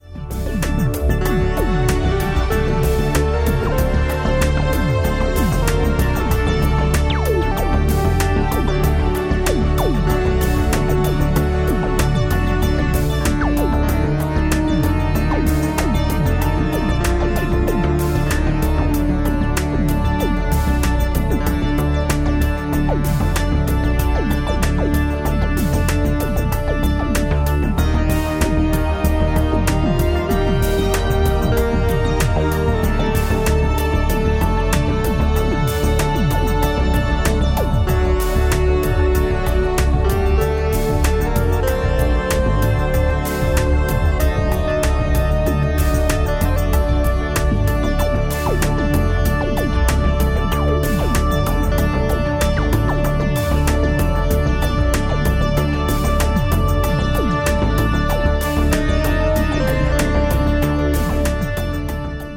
An Epic  Mystical Musical Journey Through the Sands of Time.